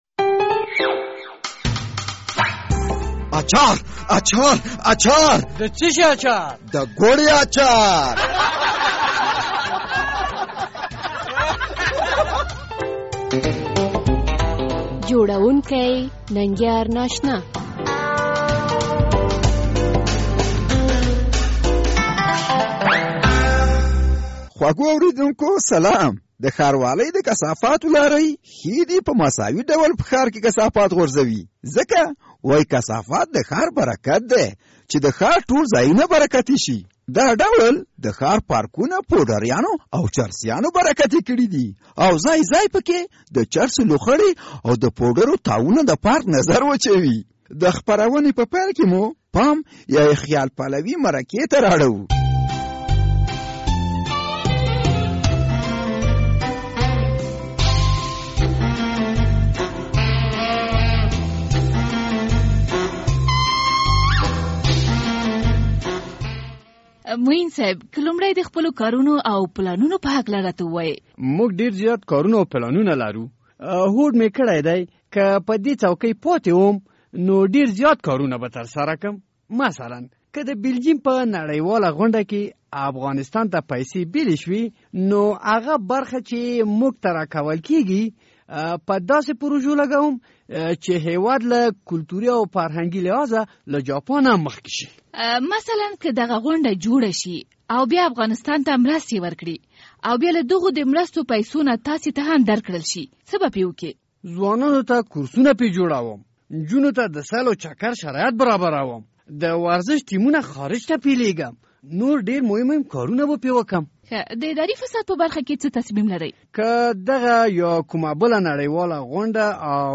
د ګوړې اچارپه دې خپرونه کې لومړی يوه خیال پلوي مرکه در اوروو.